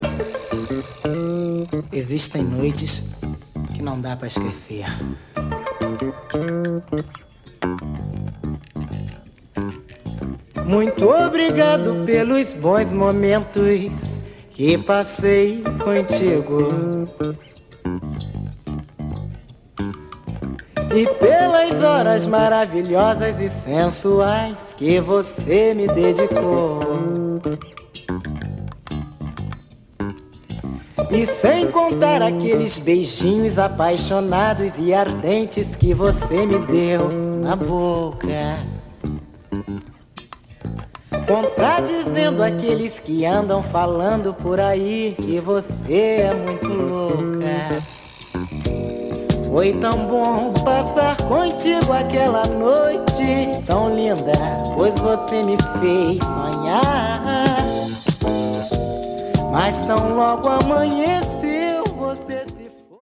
The best Disco, Funk, Soul